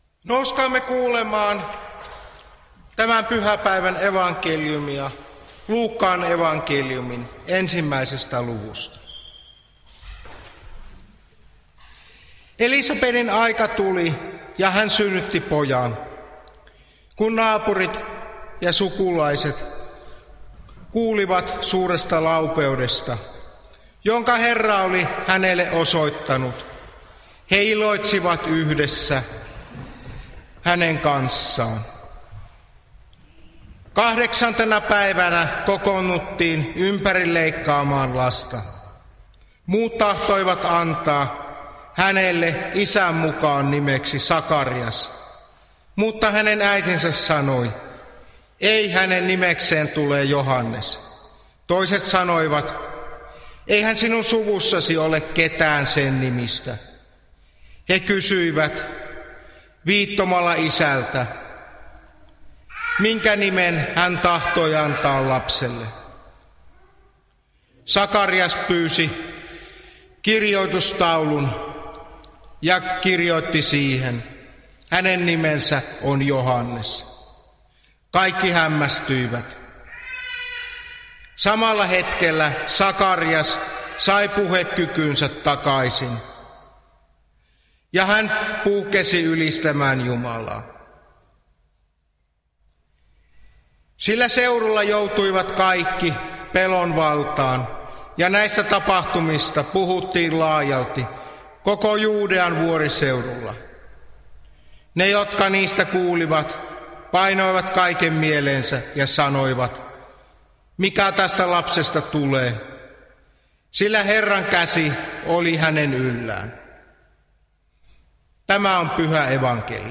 Alajärvellä juhannuspäivänä Tekstinä on Luuk. 1:57–66